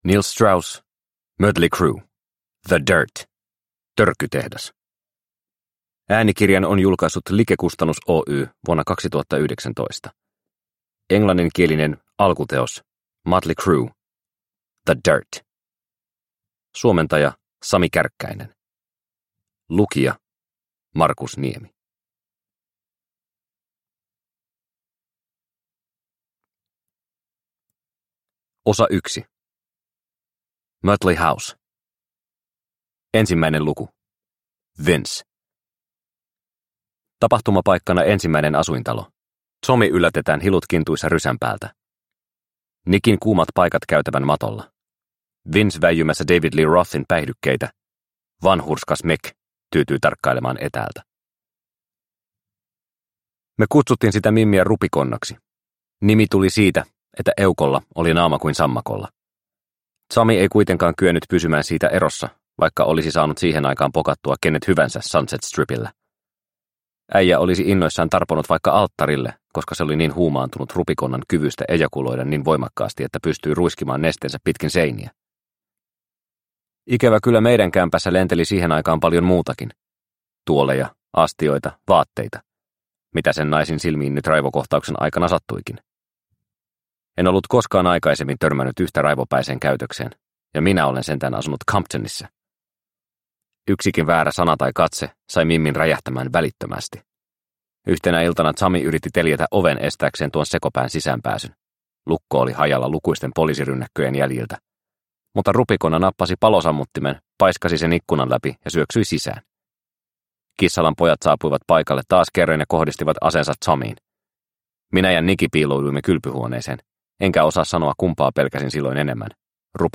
The Dirt - Törkytehdas – Ljudbok – Laddas ner